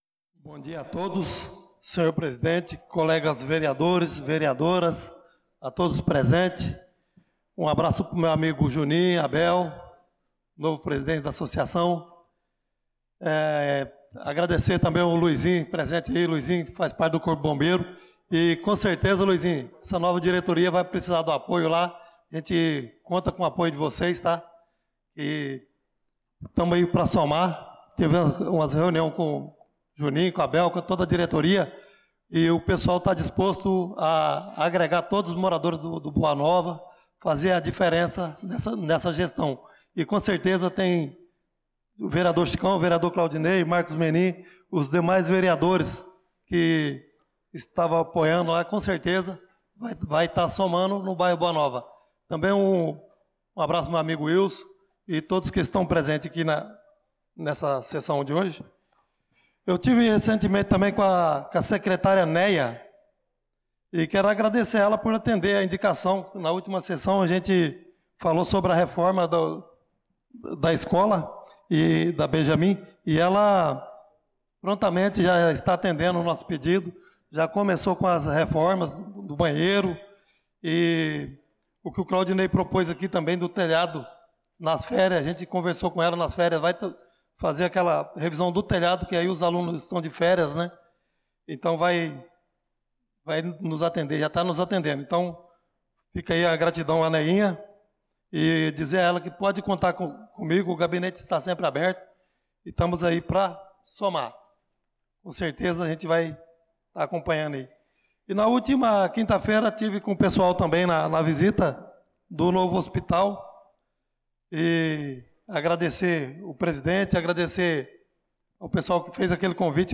Pronunciamento do vereador Chicão Motocross na Sessão Ordinária do dia 26/05/2025